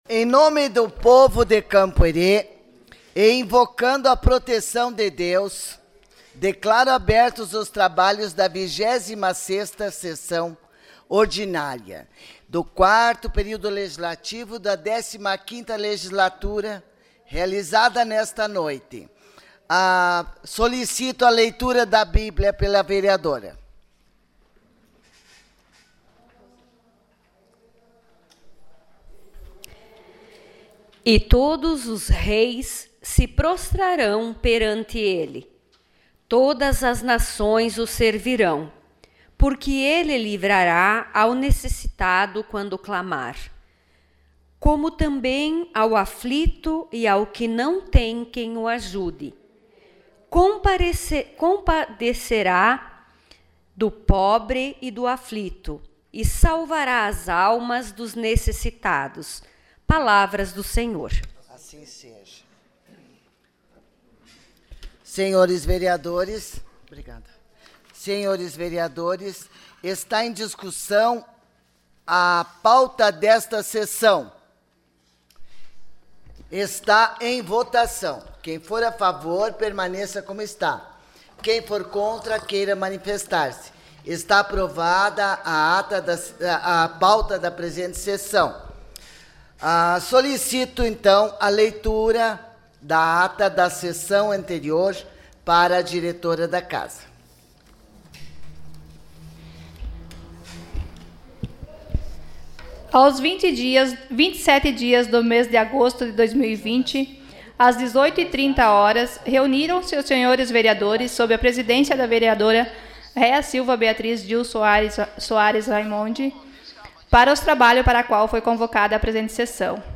Sessão Ordinária dia 04 de setembro de 2020